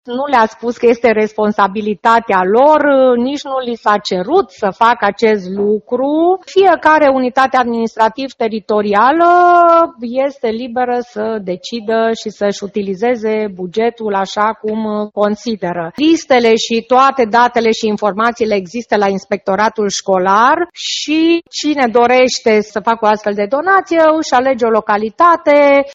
Tocmai de aceea, instituția a lansat un apel, alături de Inspectoratul Școlar Județean, către cei care pot dona sau achiziționa echipamente, spune prefectul Liliana Oneț: